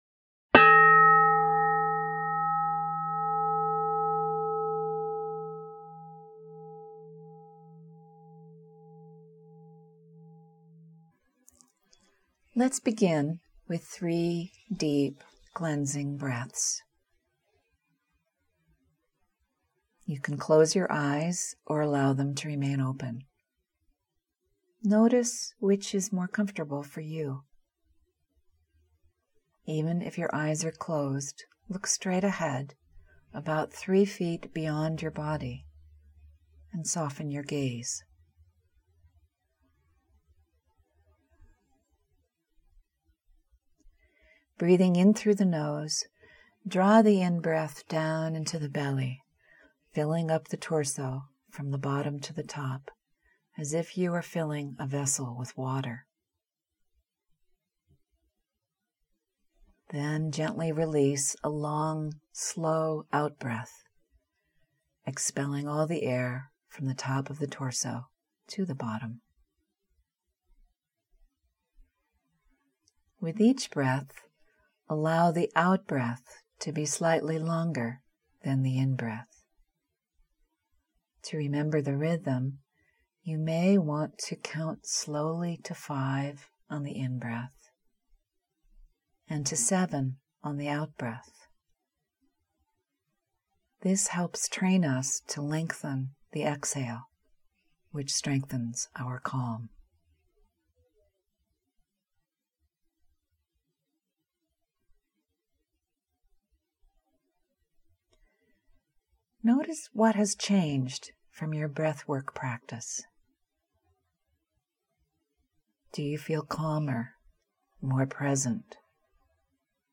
Guided Meditations